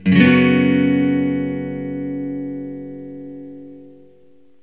Fssus4.mp3